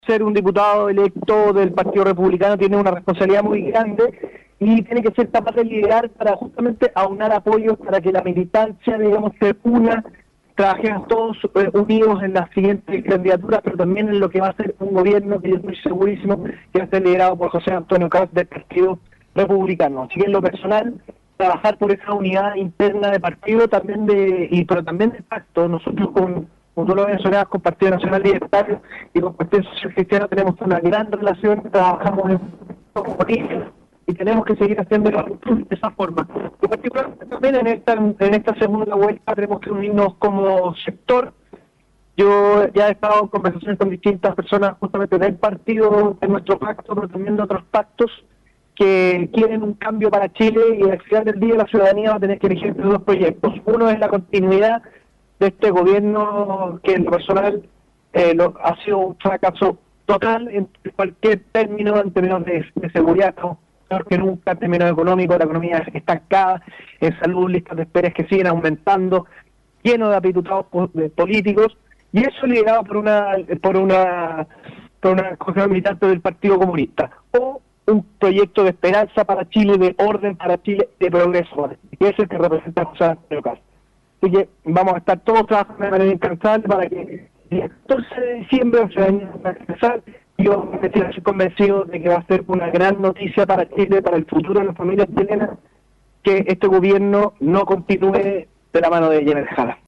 El diputado electo Sebastián Cristofanini, primera mayoría de la lista del Partido Republicano en el Distrito 16, conversó con Punto de Vista